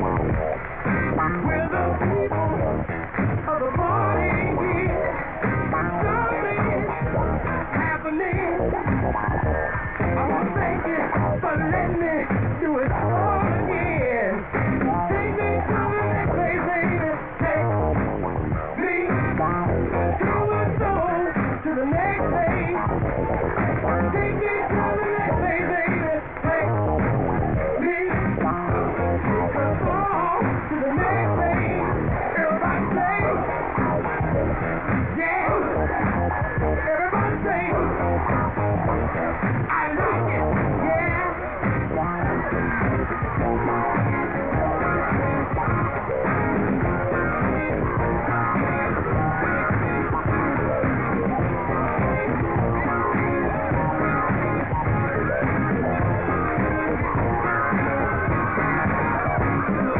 70's/DISCO